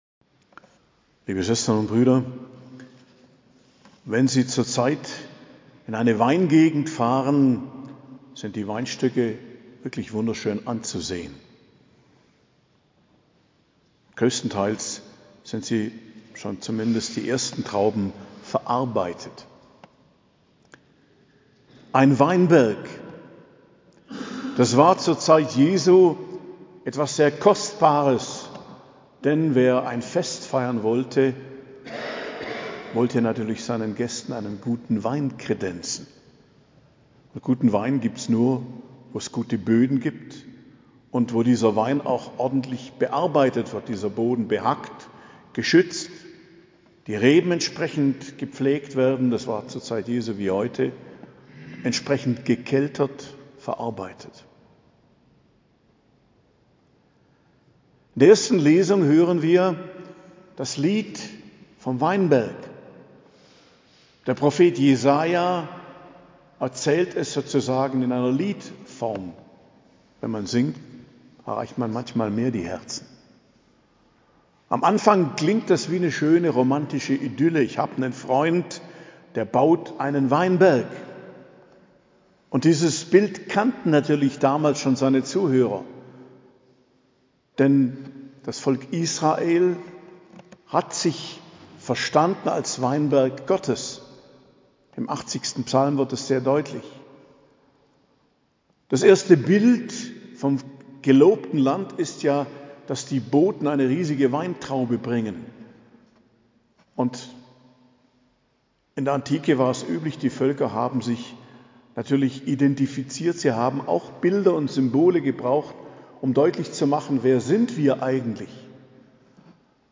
Predigt zum 27. Sonntag i.J., 8.10.2023 ~ Geistliches Zentrum Kloster Heiligkreuztal Podcast